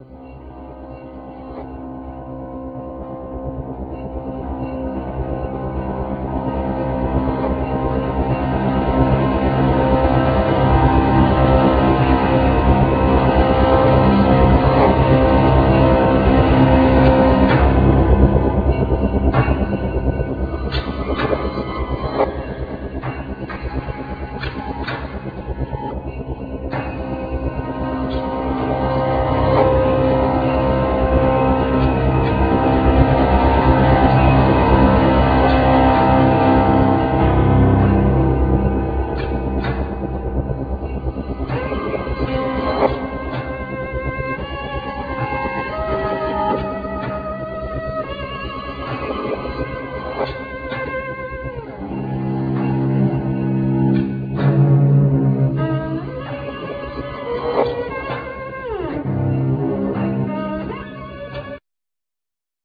Piano, Keyboards
Voice
Cello
Rhythm programming, Sample, Loops
Live erectronics